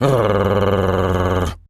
pgs/Assets/Audio/Animal_Impersonations/dog_growl_01.wav at master
dog_growl_01.wav